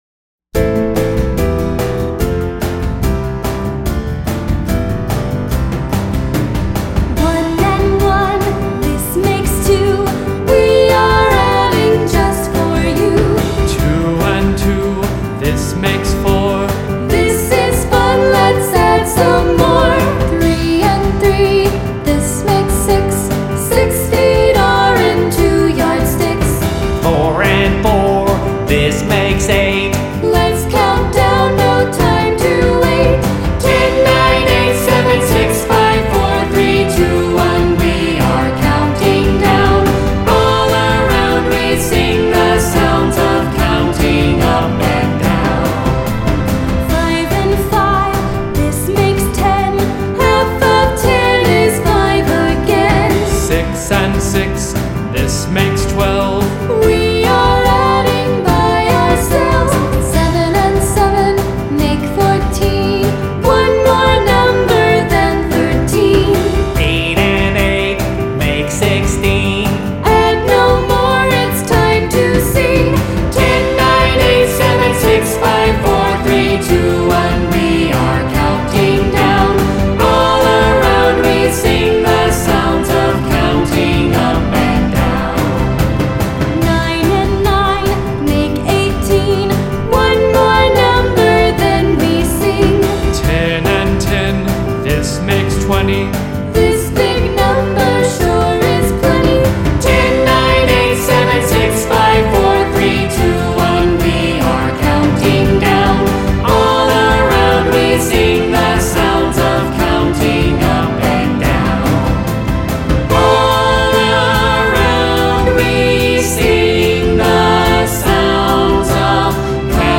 Children’s Songs